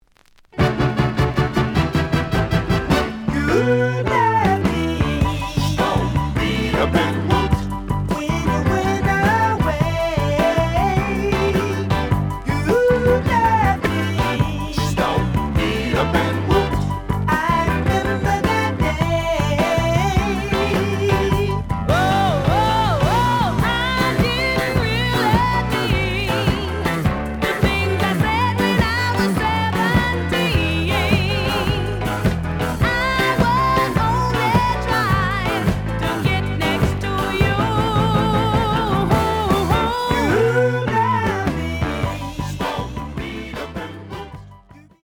The audio sample is recorded from the actual item.
●Genre: Funk, 70's Funk
Slight edge warp.